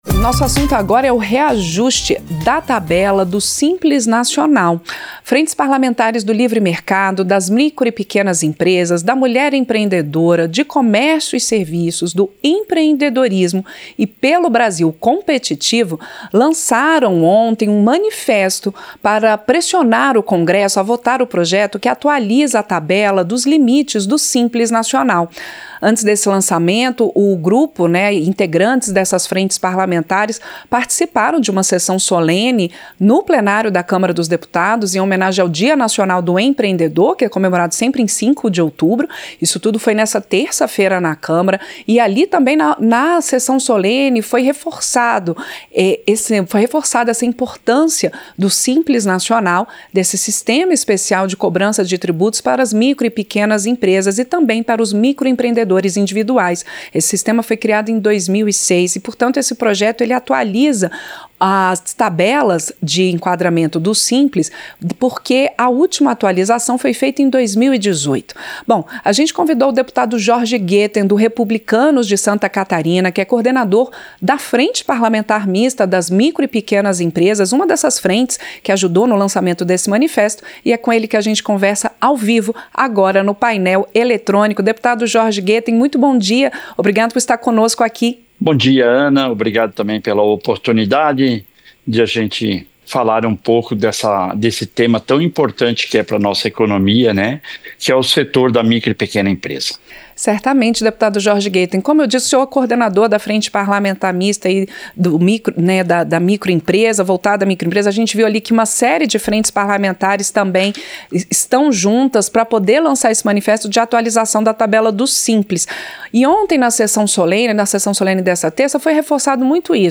Entrevista - Dep. Jorge Goetten (Rep-SC)